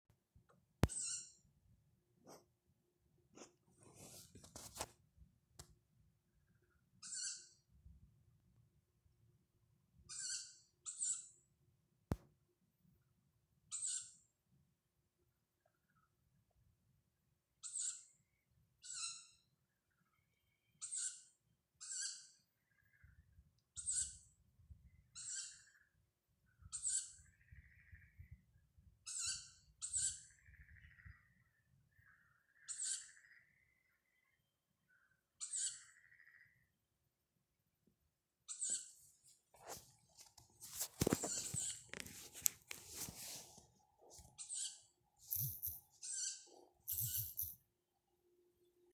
Tawny Owl, Strix aluco
Count2 - 3
StatusSinging male in breeding season
Vakarā piemājas kokos dzirdama pūču sasaukšanās.Balsis nāk no divām pusēm.